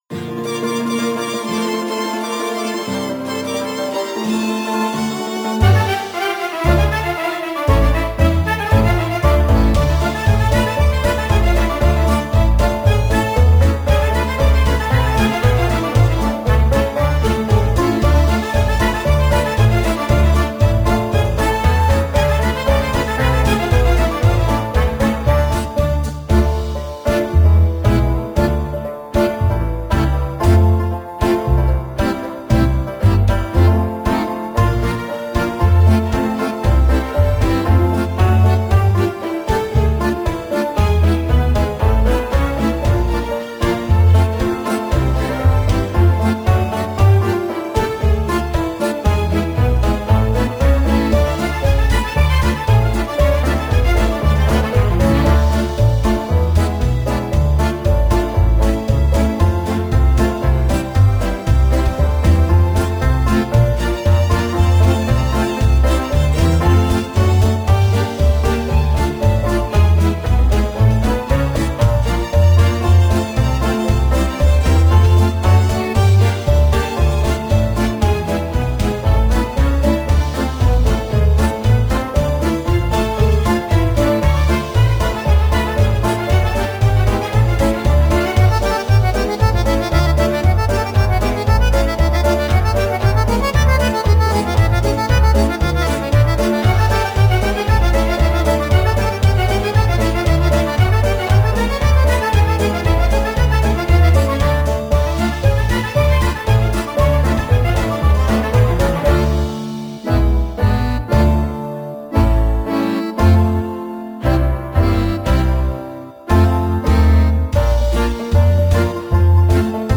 Плюсовка